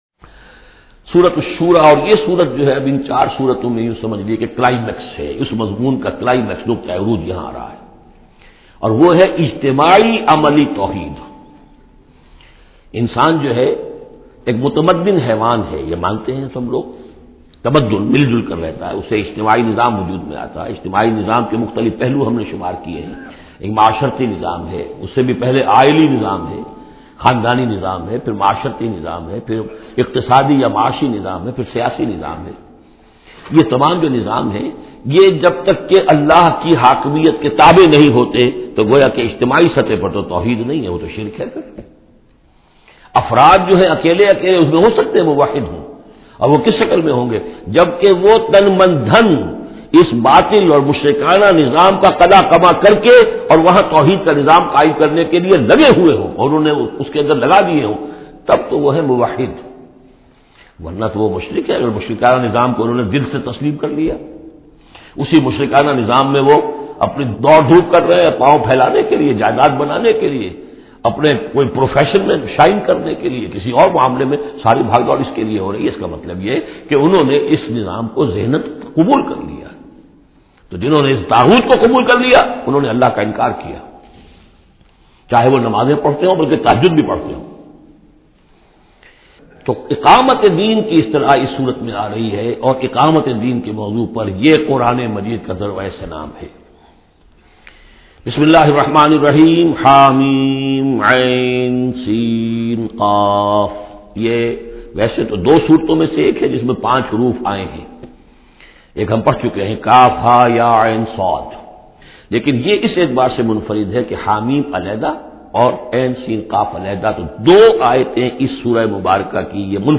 Surah Ash-Shura is 42nd chapter of Holy Quran. Listen online mp3 urdu tafseer of Surah Ash-Shura in the voice of Dr Israr Ahmed.